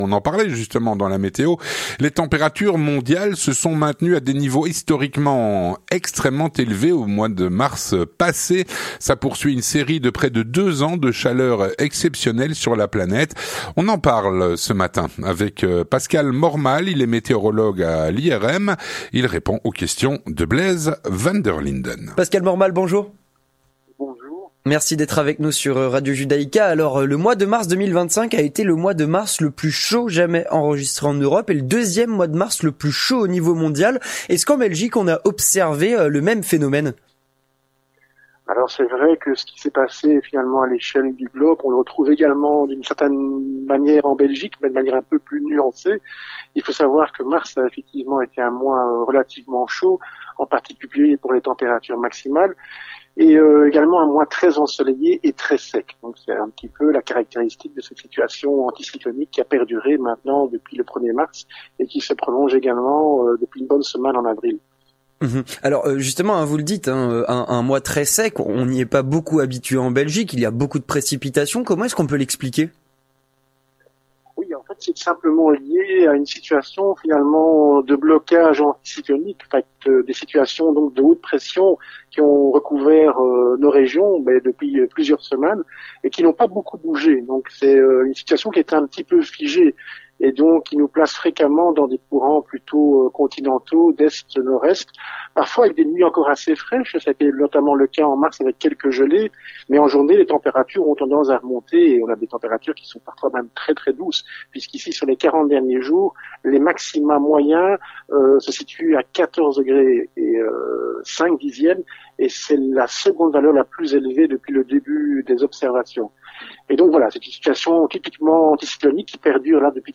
L'entretien du 18H - Les températures mondiales se sont maintenues à des niveaux historiquement élevés en mars.